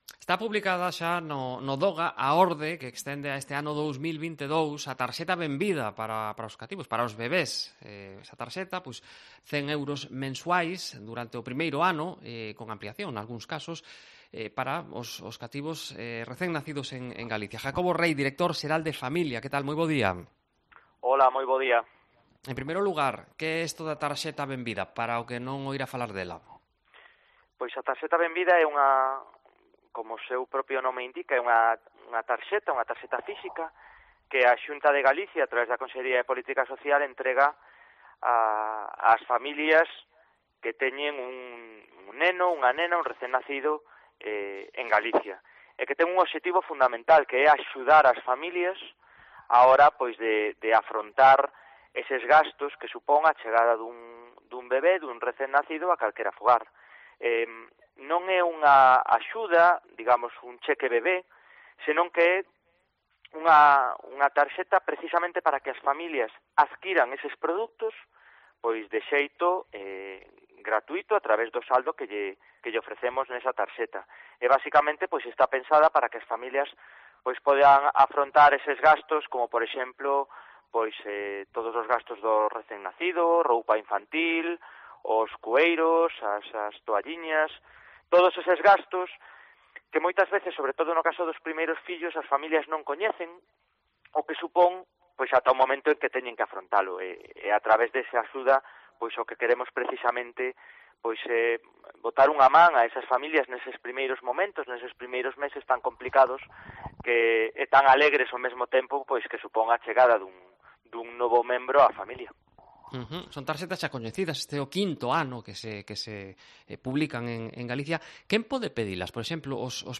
Entrevista con Jacobo Rey, director xeral de Familia da Xunta